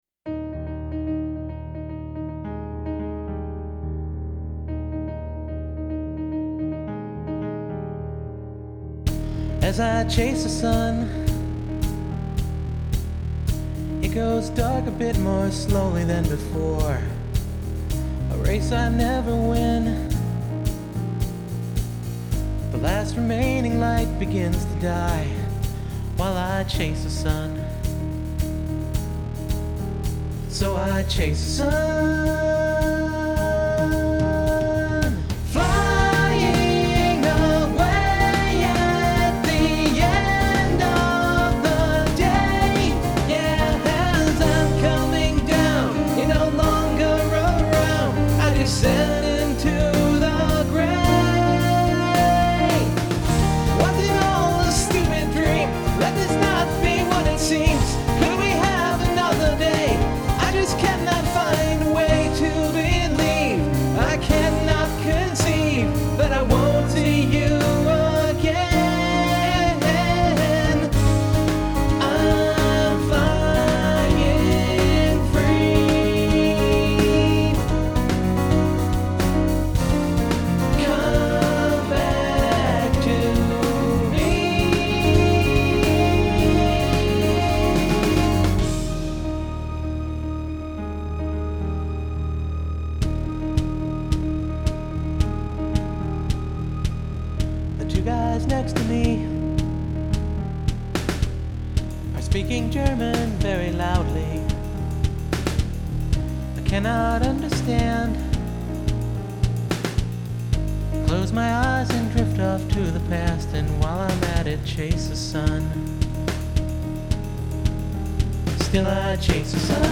It is about my mom, and the plane trips back and forth to Michigan as I dealt with her dying. Getting through the last verse took (not kidding) about 3 dozen takes because I broke up while trying to sing it.